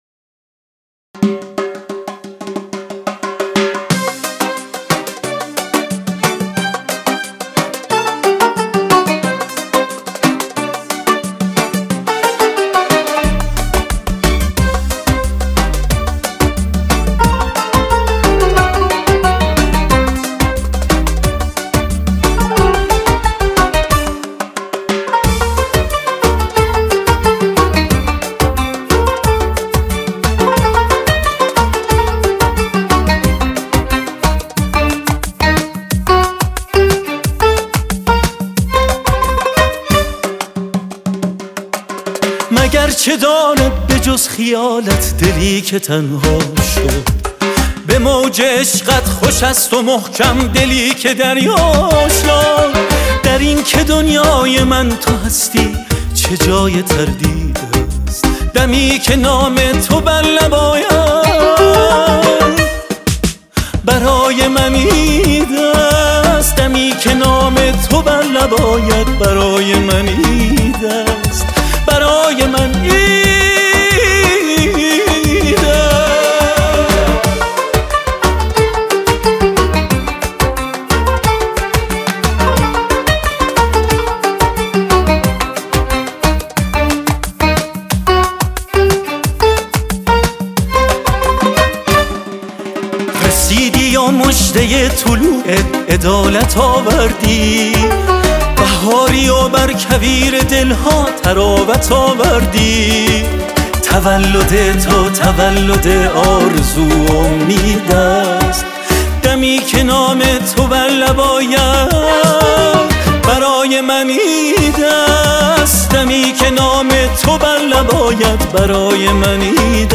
خوانندگی